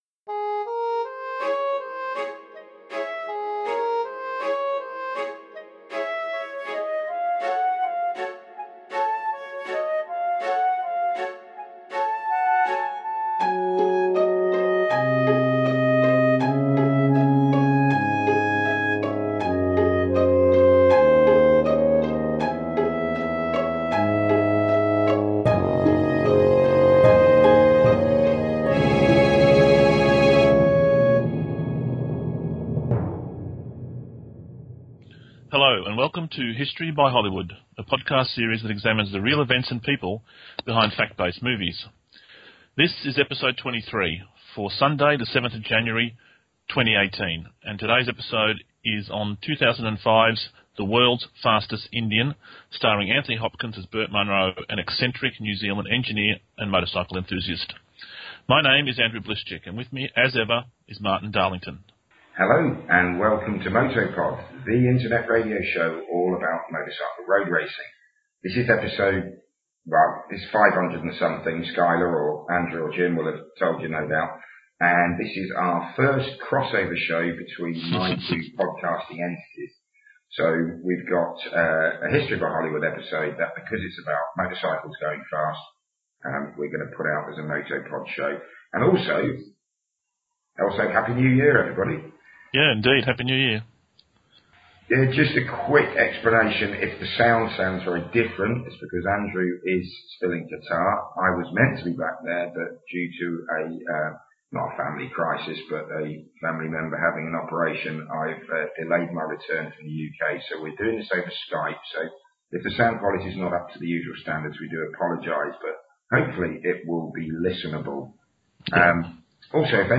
We also apologise for the poor sound quality; as you will discover in the introduction it was due to pressing reasons that we had to improvise this week’s episode and we will return to the usual standard for the next show.